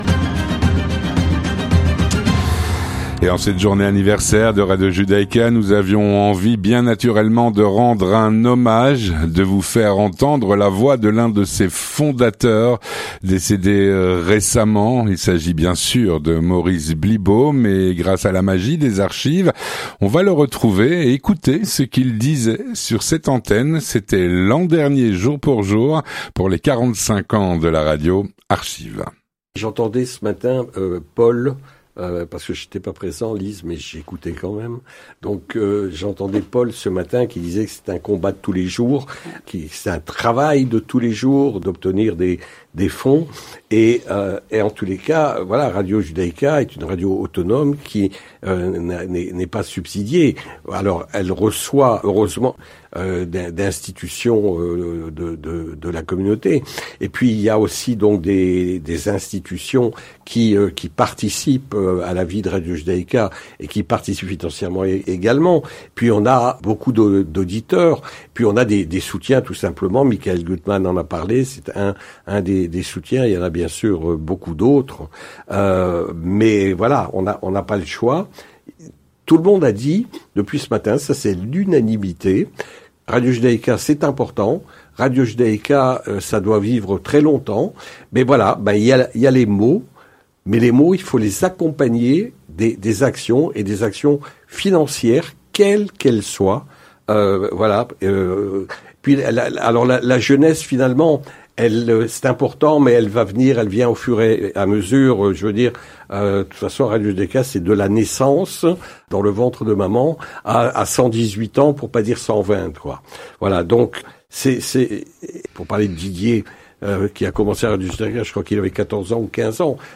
Avec la magie des archives, écoutez ce qu’il disait sur cette antenne l’an dernier pour les 45 ans de la radio. archives